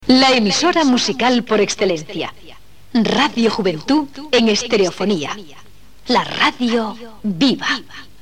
Indicatiu de l'emissora "en estereofonía"
FM